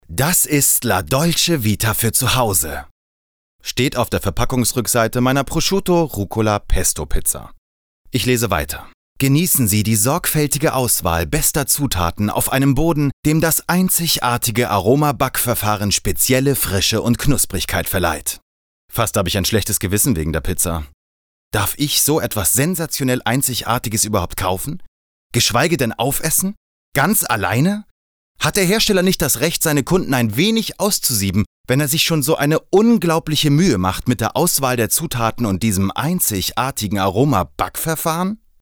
Tommy Jaud - Pizza - Werbung / Hörbuch